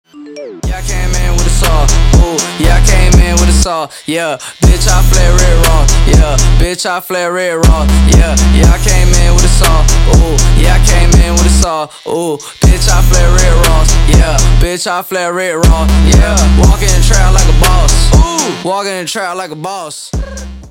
• Качество: 320, Stereo
громкие
мощные басы
Trap
Bass